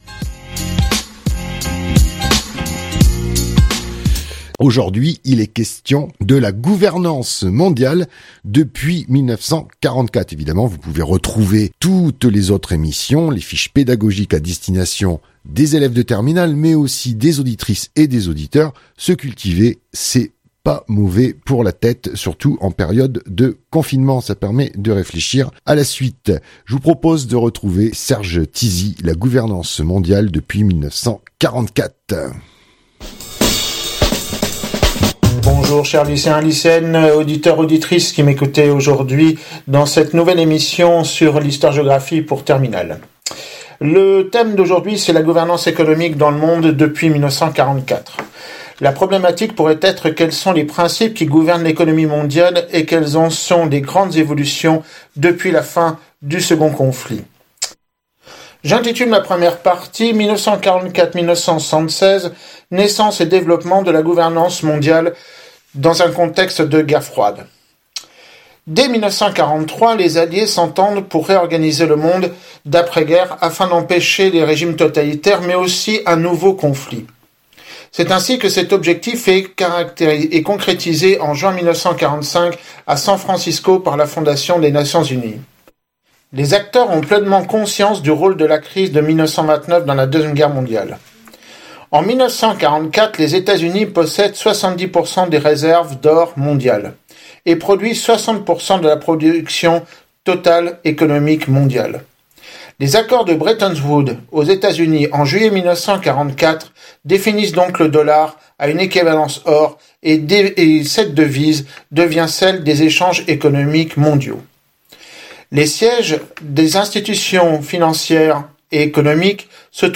Cours radiophonique histoire géographie de terminale
enregistré chez lui